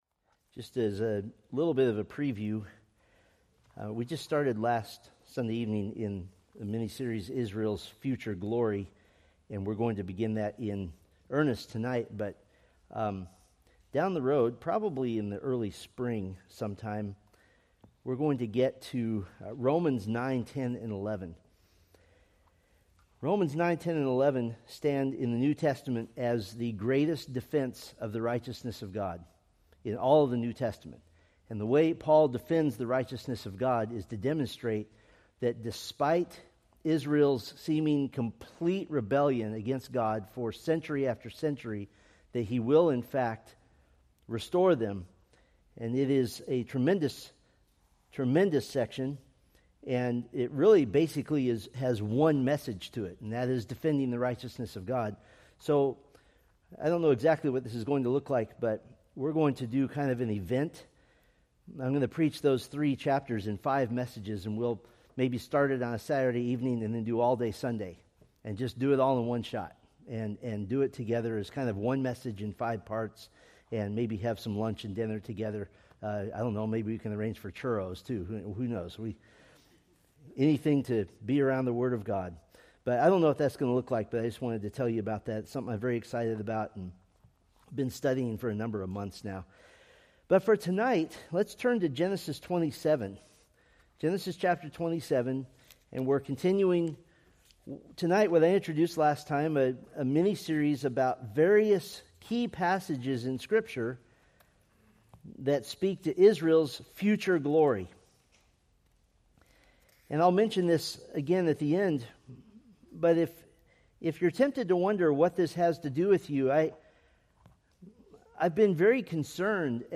Preached October 12, 2025 from Selected Scriptures